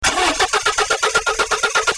Normal engine cranking sound
starter_cranking.mp3